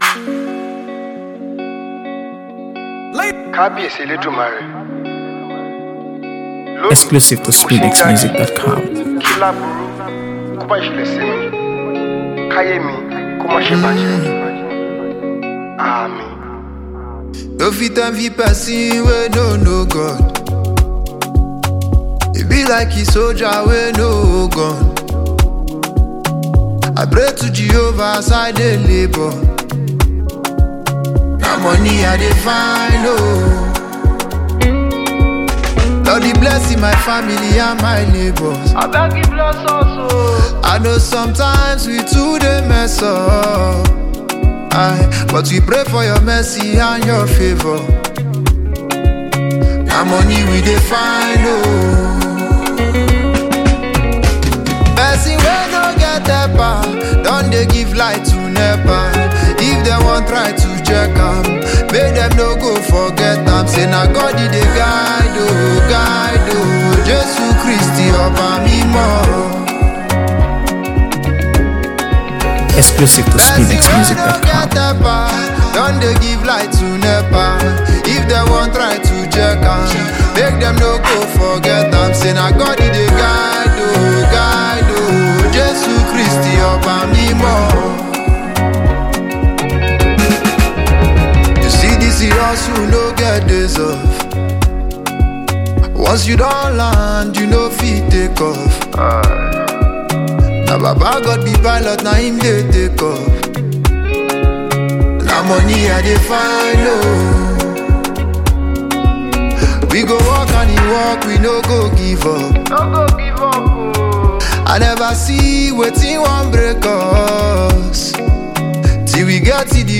AfroBeats | AfroBeats songs
with a vibrant melody and infectious hooks
With its catchy rhythm and memorable lyrics